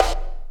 just SNARES 3
snarefxldk14.wav